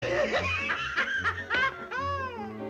Tom Laugh 1